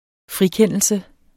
frikendelse substantiv, fælleskøn Bøjning -n, -r, -rne Udtale [ ˈfʁiˌkεnˀəlsə ] Betydninger 1. det at erklære nogen uskyldig i en anklage Synonym frifindelse I byretten blev han idømt to et halvt års fængsel.